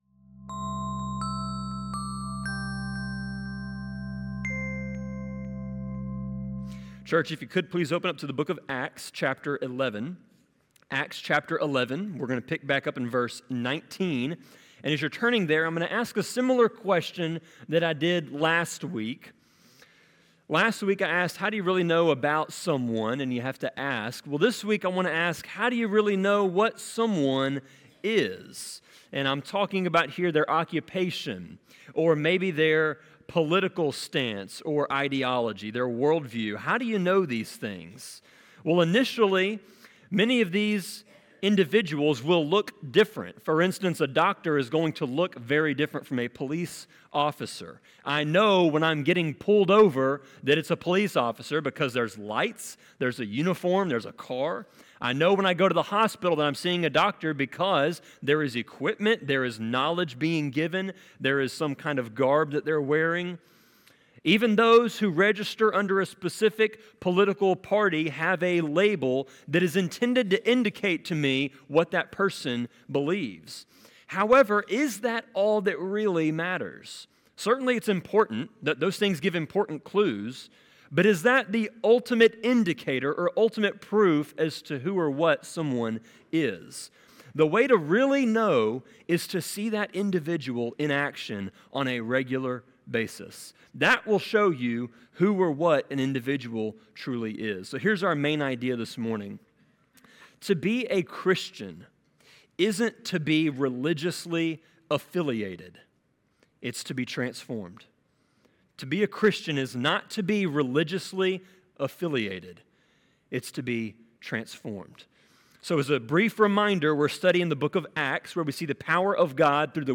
Sermon-24.5.12.m4a